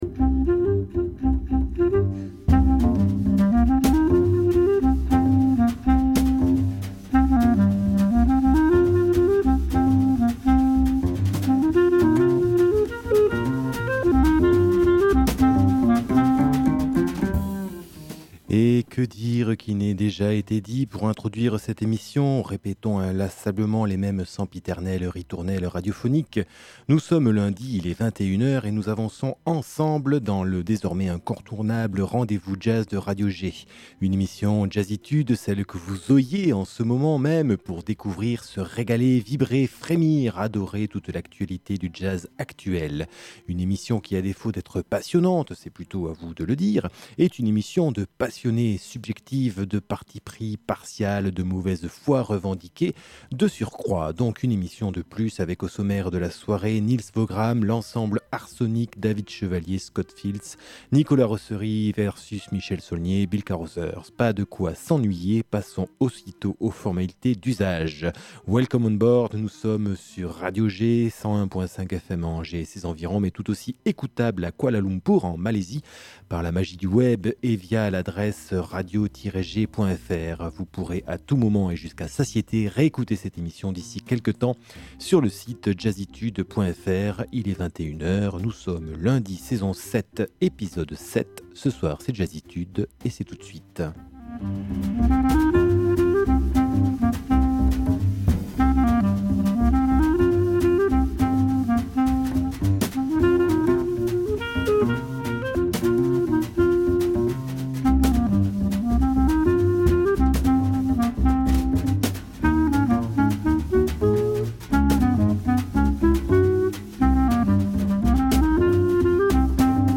où l'on se fait une émisison toute douce, éthérée, évanescente comme si l'on avait fumé un gros pétard d'herbe verte...